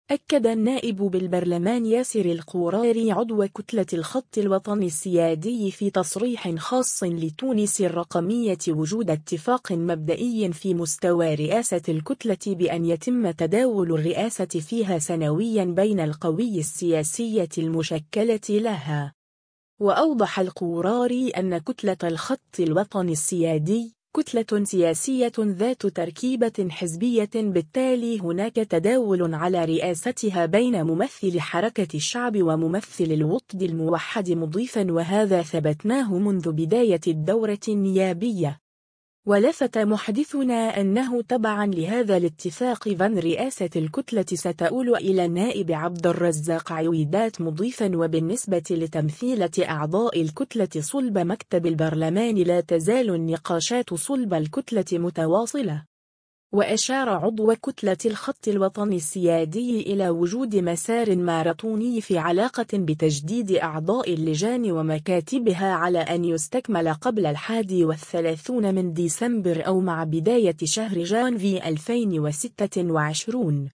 أكد النائب بالبرلمان ياسر القوراري عضو كتلة الخط الوطني السيادي في تصريح خاص لـ”تونس الرقمية” وجود اتفاق مبدئي في مستوى رئاسة الكتلة بأن يتم تداول الرئاسة فيها سنويا بين القوى السياسية المشكلة لها.